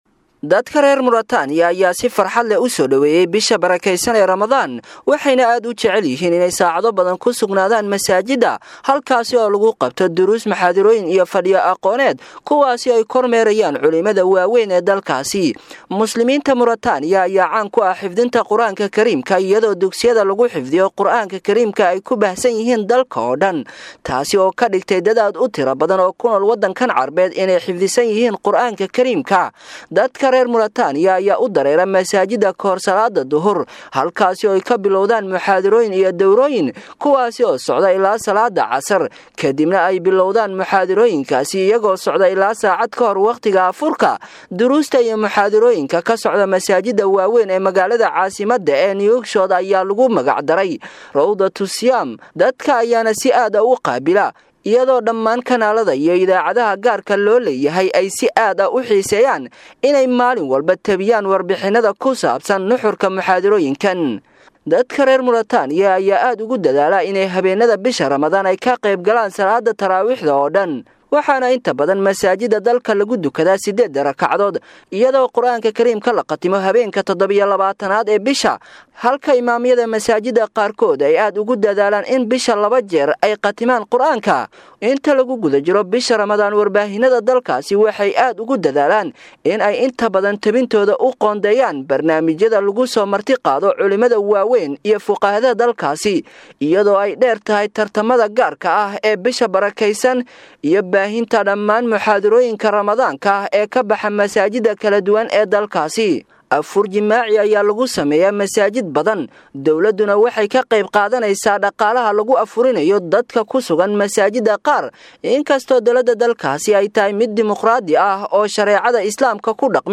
Maxaa Lagu Yaqaanaa Muslimiinta ku Nool Murutaaniya Marka la Gaaro Bisha Ramadaan?[WARBIXIN]